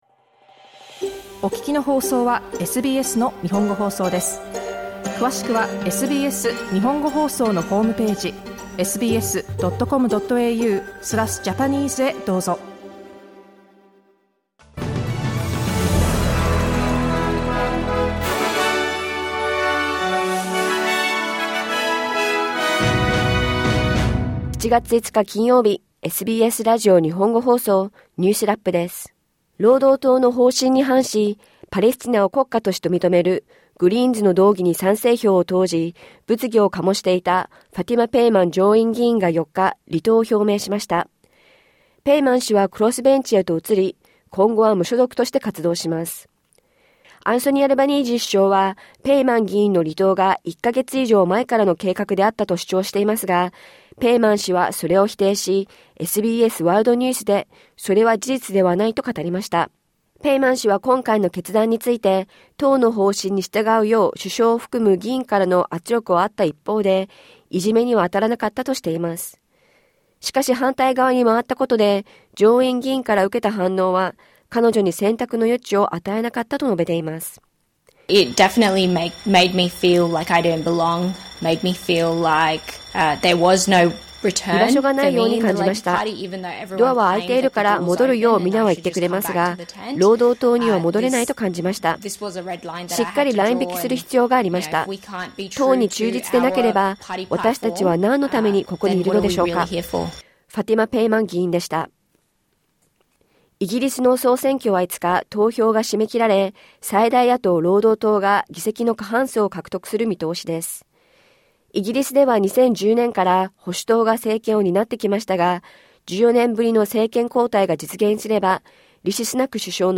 労働党の方針に反し、パレスチナを国家として認めるグリーンズの動議に賛成票を投じ、物議を醸していたファティマ・ペイマン上院議員が4日、離党を表明しました。1週間のニュースを振り返るニュースラップです。